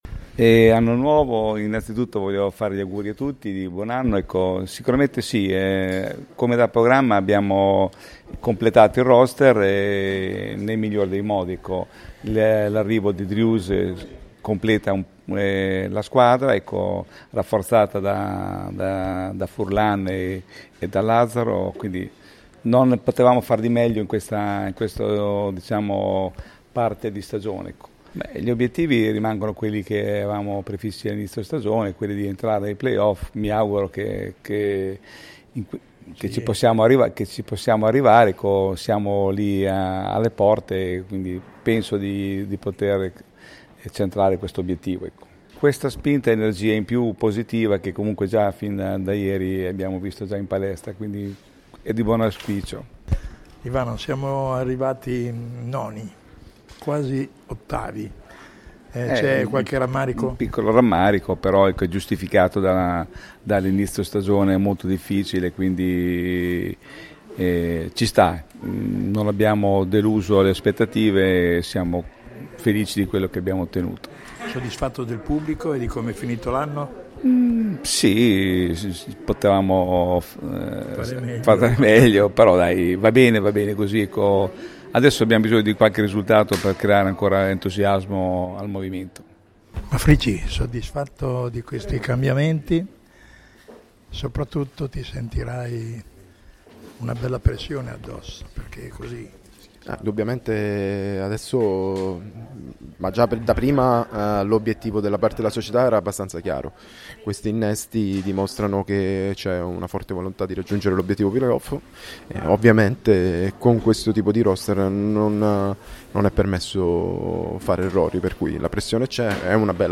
Le tre ragazze sono chiamate, grazie alla loro forte esperienza, ad alzare il già ottimo tasso tecnico della squadra, per raggiungere i Play-Off. Le nostre interviste